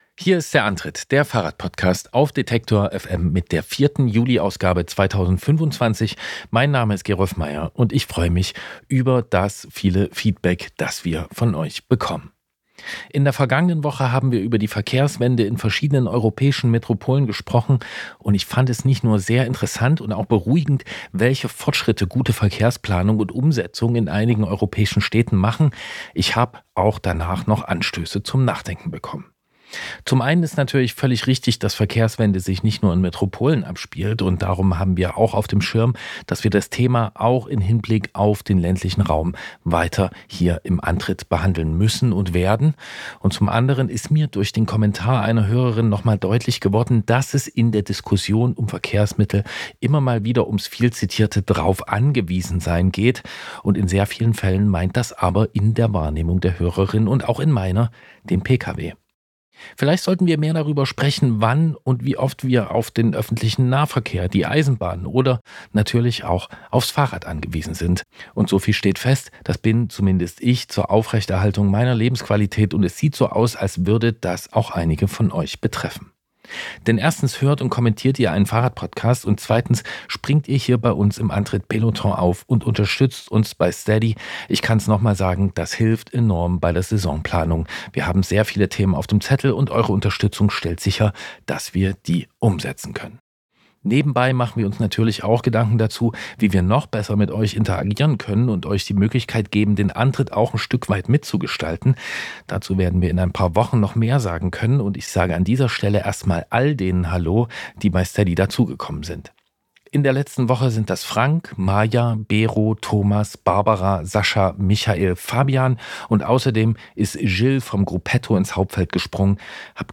Auf der Eurobike sprechen wir über das Netzwerk und seine Ziele.